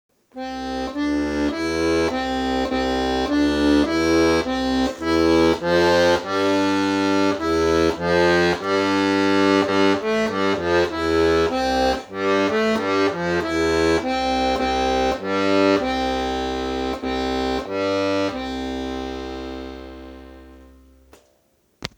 Ich habe dir mal ein Beispiel mit Bass aufgenommen (nur Handy, keine Nachbearbeitung), da du den Helikonbass (vermutlich) toll findest.